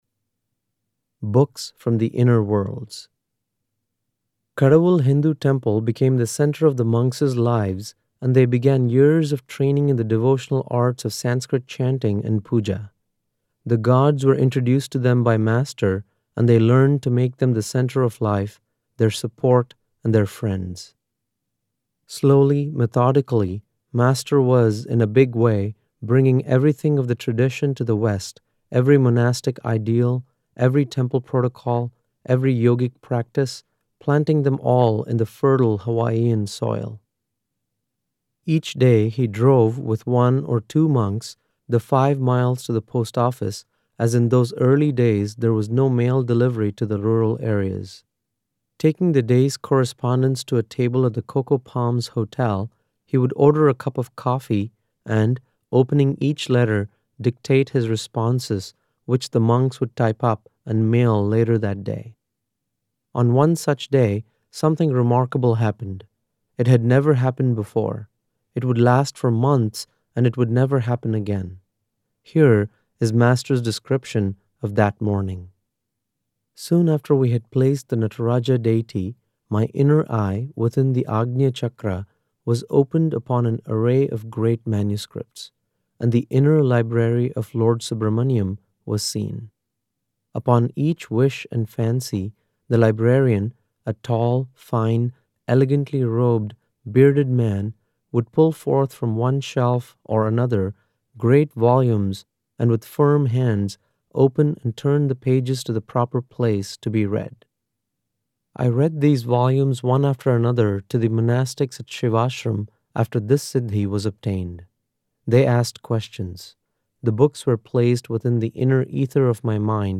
An Audio Story from The Guru Chronicles